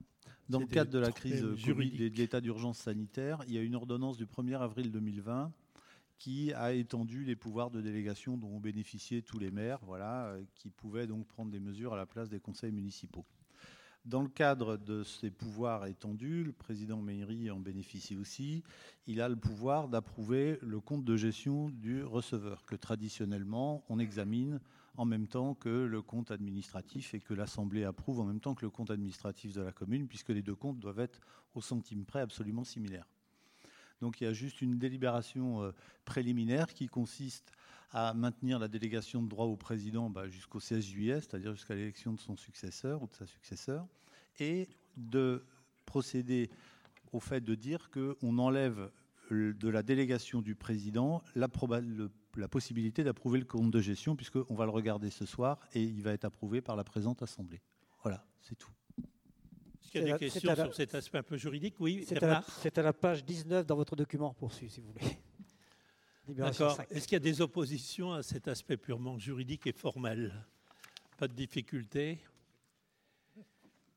Audio du conseil communautaire du 19 juin 2020
Consultez ci-dessous les pistes de lecture audio du conseil communautaire du 19 juin 2020.